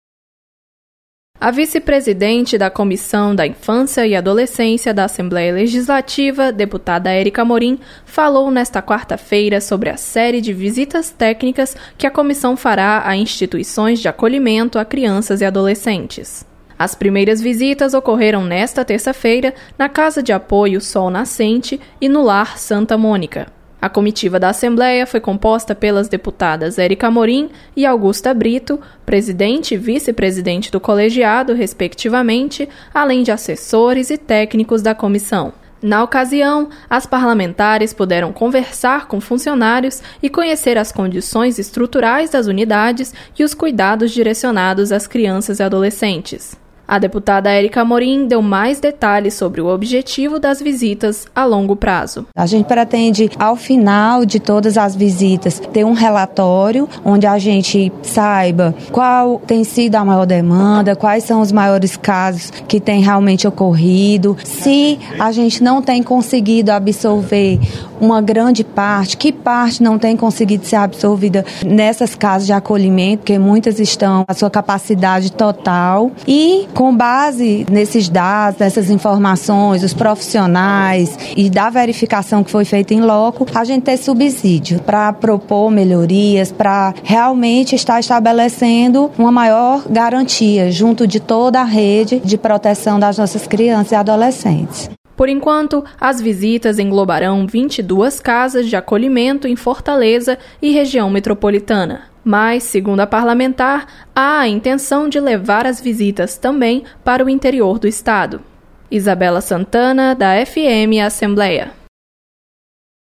Deputada comenta visitas a instituições de acolhimento de crianças e adolescentes.